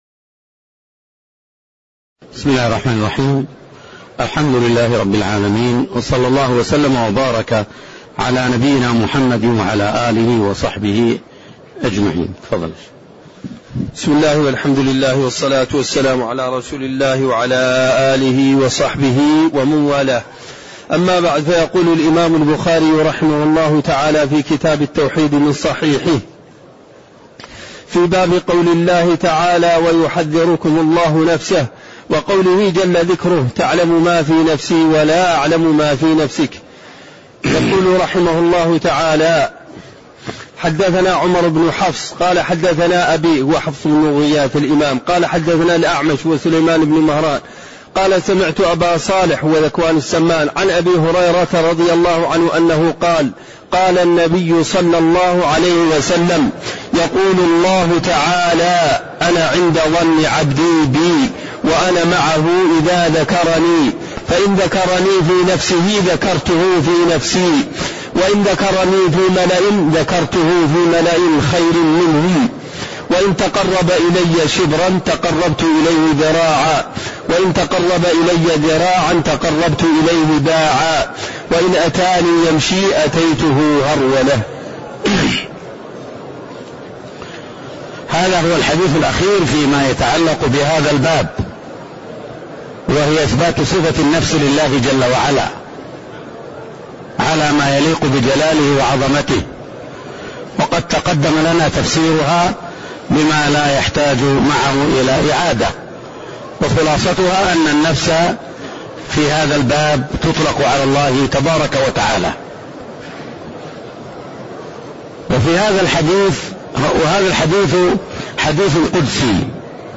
تاريخ النشر ٢٦ ربيع الثاني ١٤٣٣ هـ المكان: المسجد النبوي الشيخ